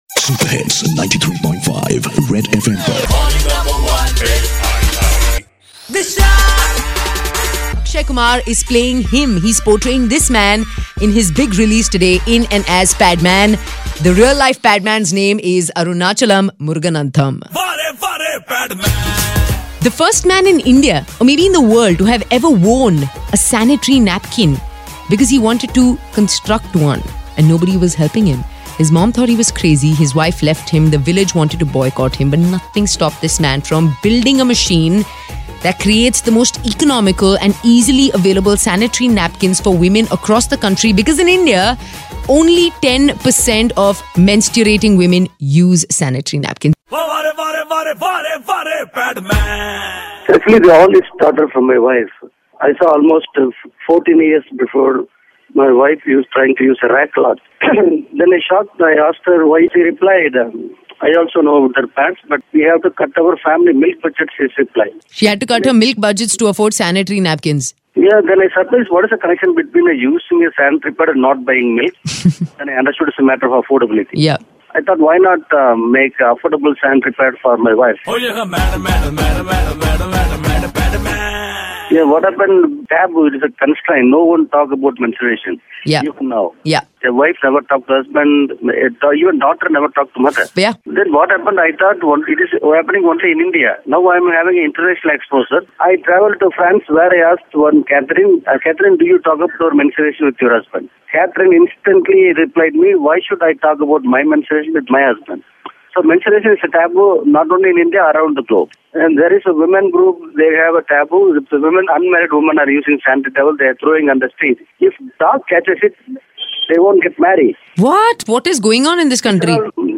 Interview of Real Padman Arunachalam Muruganantham